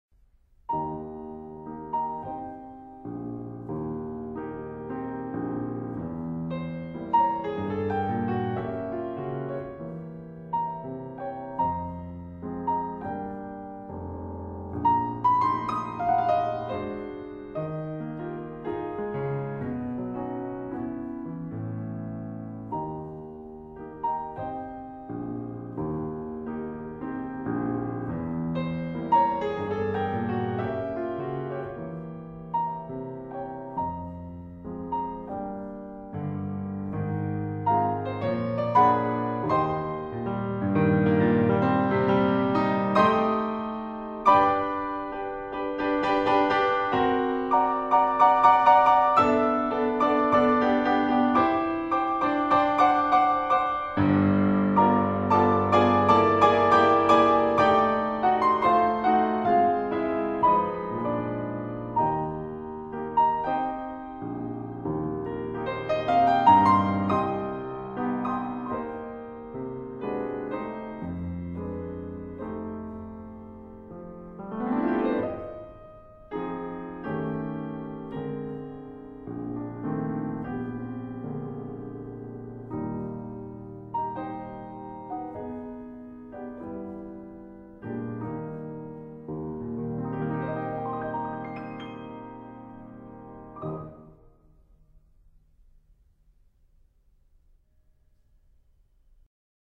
Angenehme und dezent unterhaltende Klaviermusik